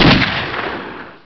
u-shoot.wav